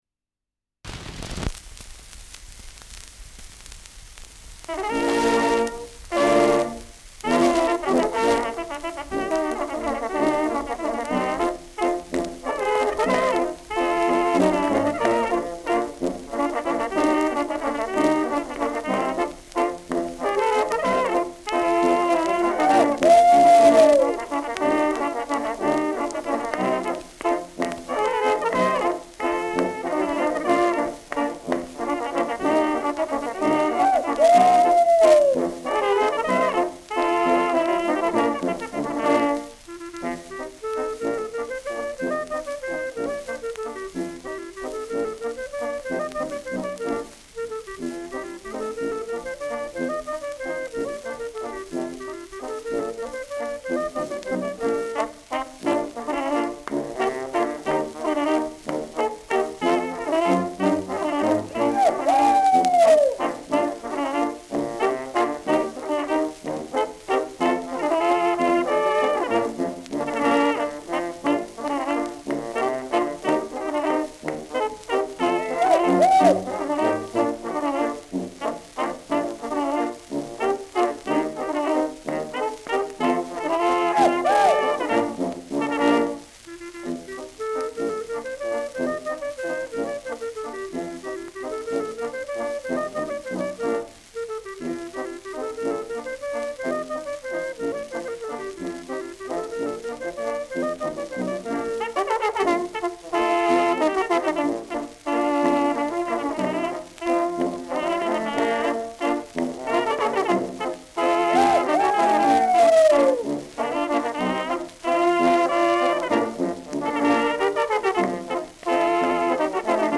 Schellackplatte
leichtes Rauschen
Innviertler Bauern-Trio (Interpretation)